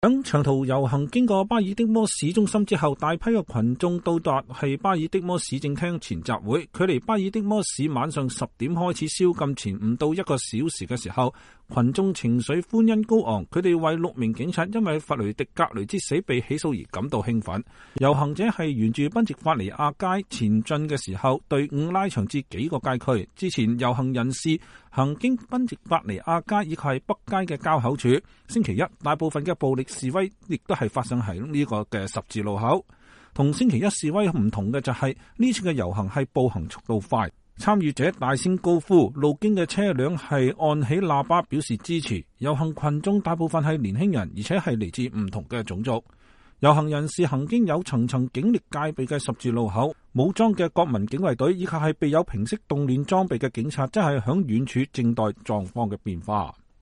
在長途遊行經過巴爾的摩市中心之後，大批群眾來到巴爾的摩市政廳前集會。距離巴爾的摩市晚上10 點開始宵禁前不到一小時之際，群眾情緒歡欣高昂，他們為 6 名警察因弗雷迪.格雷之死被起訴而感到興奮。